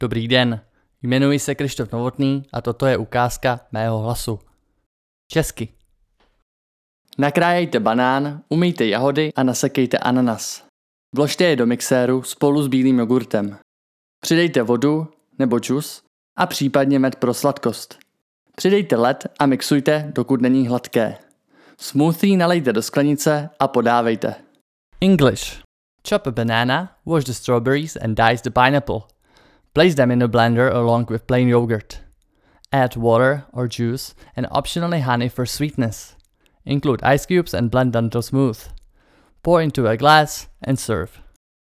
Profesionální hlasový nadhled od zkušeného učitele angličtiny a audioexperta!
Bez ohledu na to, zda potřebujete živý a nadšený projev nebo seriózní a klidný hlas, mám širokou škálu stylů a hlasových podání.
Nabízím čisté a křišťálově jasné nahrávky bez nežádoucího šumu.
Narration Vocal_bip_2.mp3